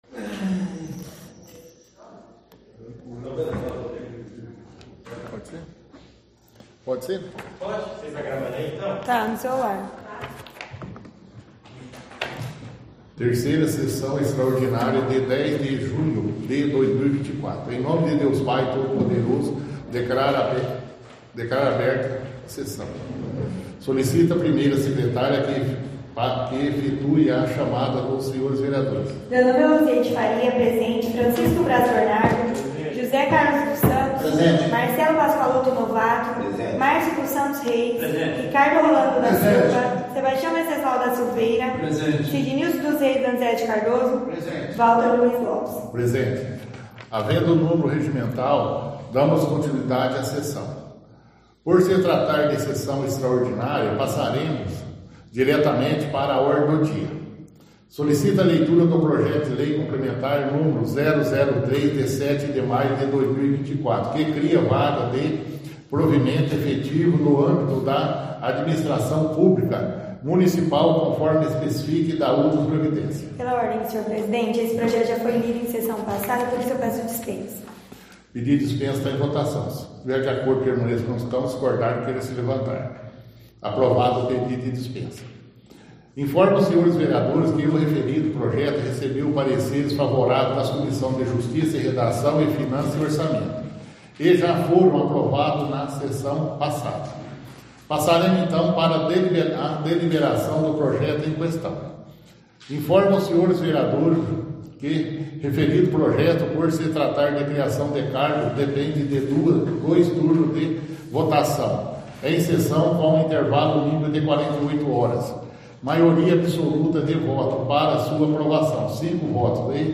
Áudio da 2ª Sessão Extraordinária – 10/06/2024